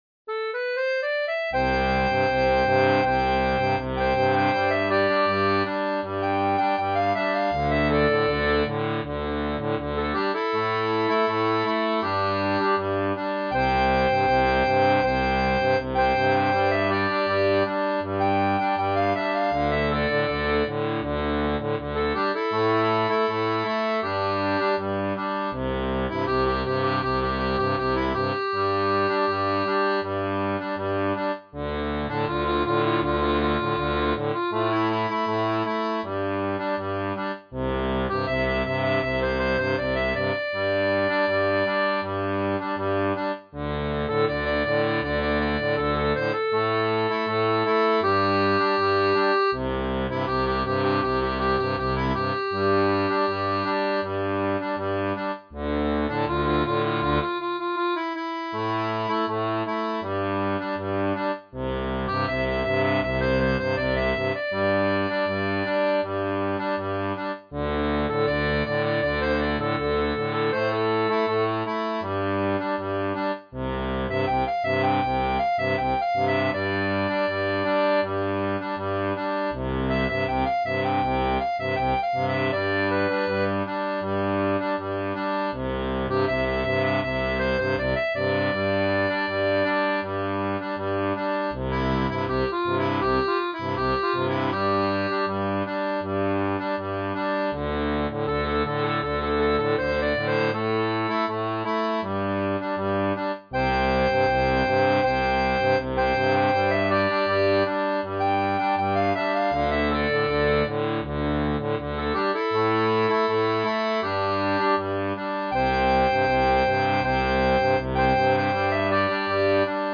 Air traditionnel portugais
Folk et Traditionnel